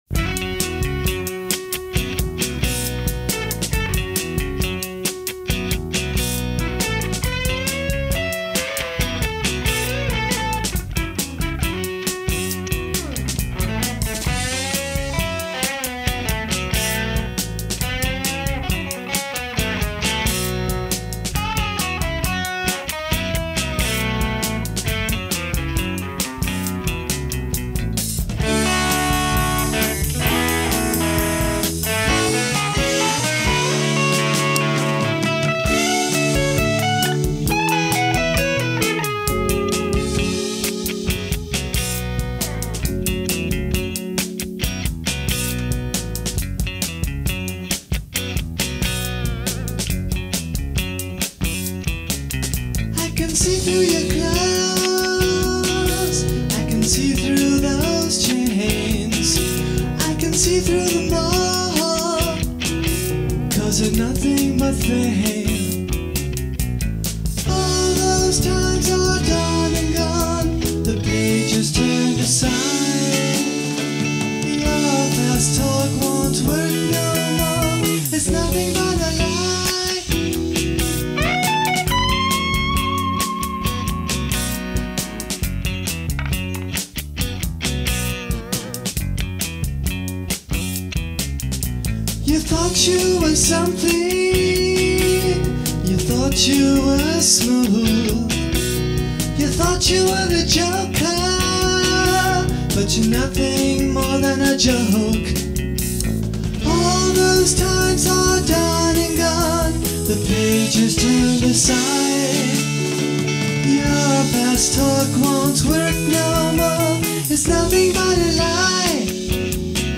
During that weekend the group recorded eleven songs on the Tascam 22-4, 4-track, reel-to-reel, tape recorder.
percussion
bass guitar
keyboards
guitar, vocals